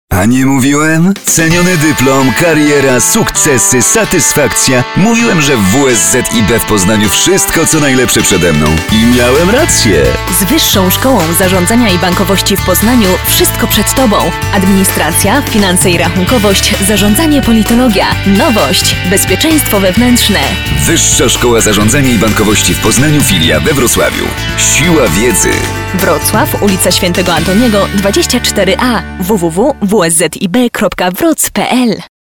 Sprecherin polnisch für TV / Rundfunk / Industrie.
Sprechprobe: eLearning (Muttersprache):
polish female voice over artist